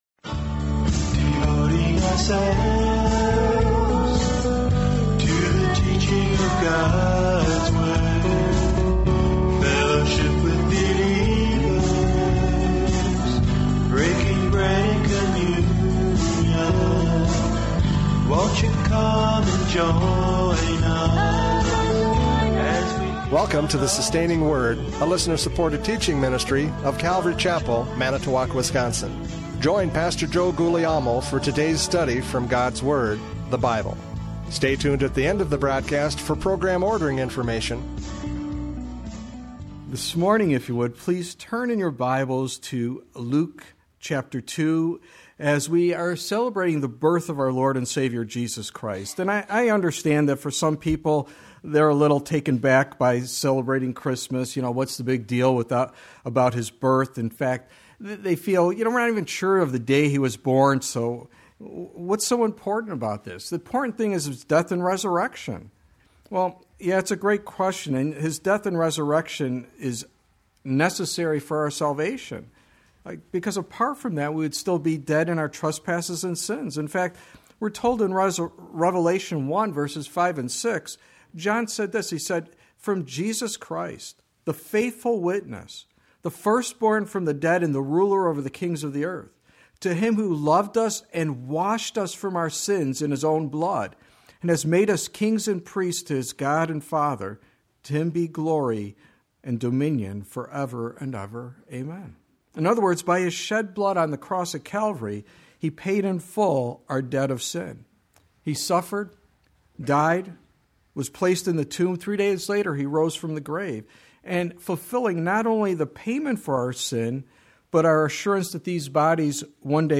Luke 2:1-7 Service Type: Radio Programs « Judges 11:29-40 A Foolish Vow!